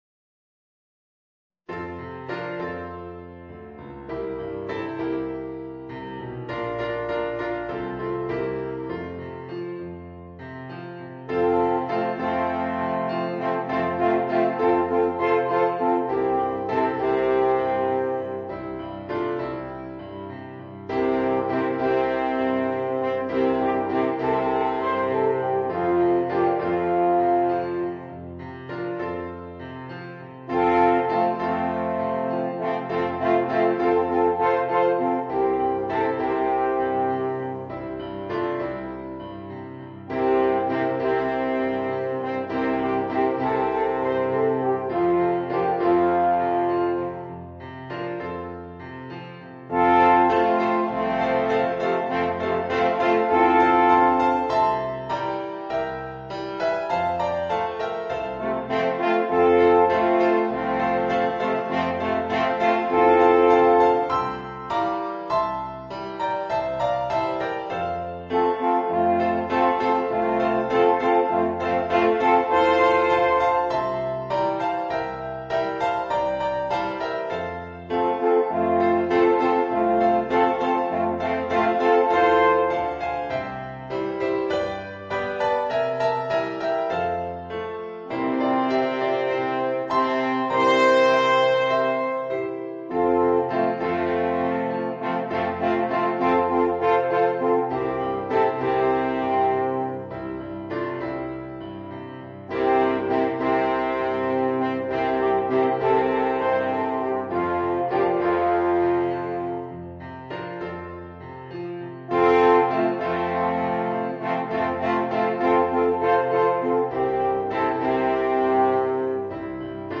Voicing: 2 Alphorns and Piano